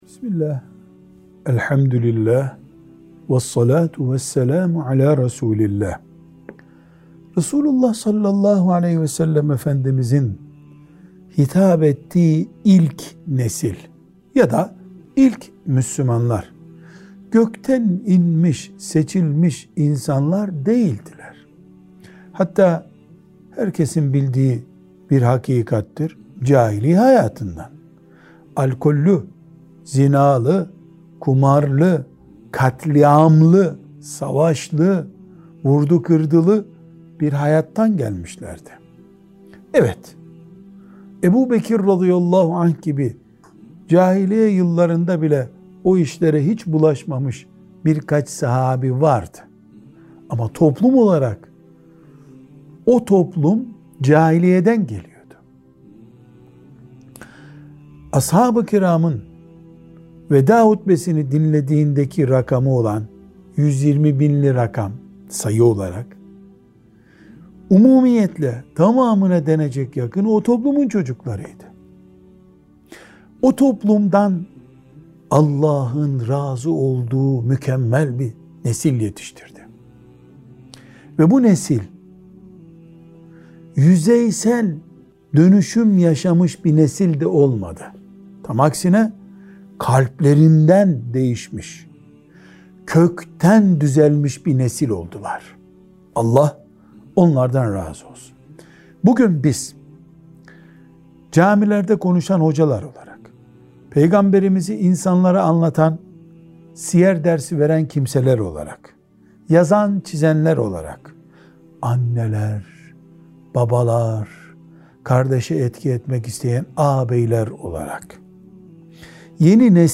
1. Sohbet Arşivi